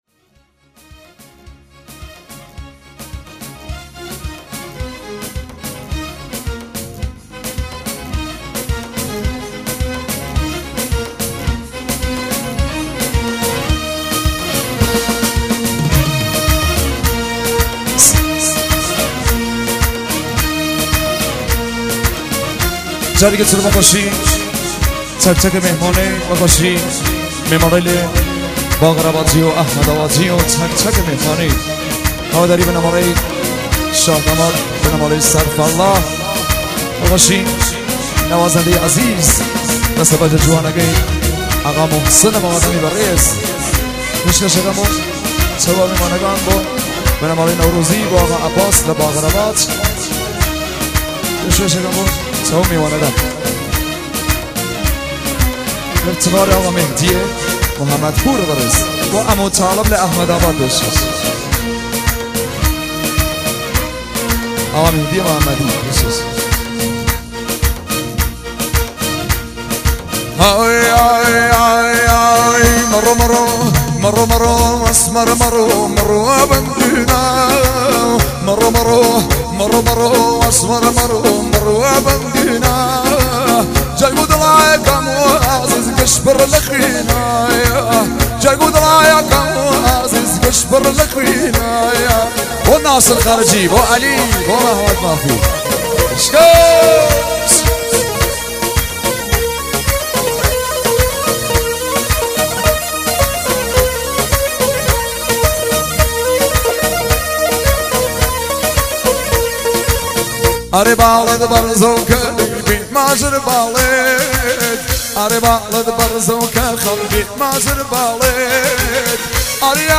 اجرا در عروسی ها